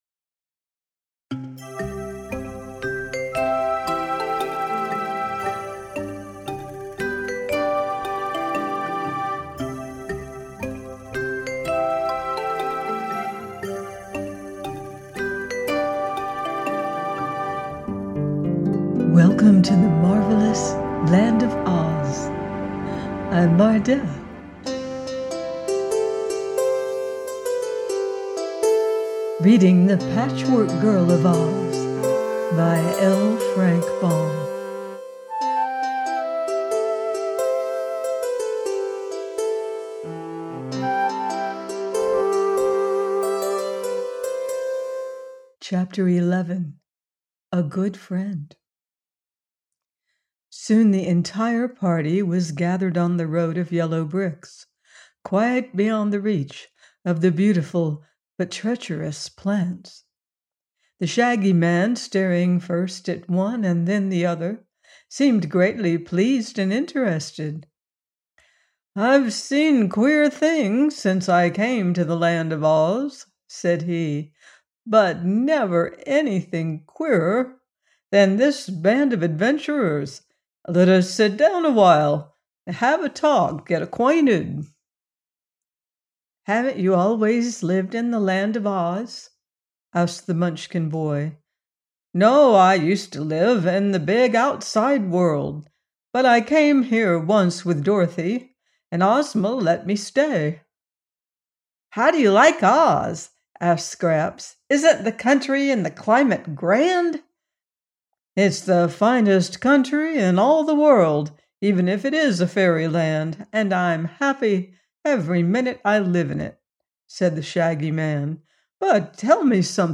The Patchwork Girl of Oz – by L. Frank Baum - audiobook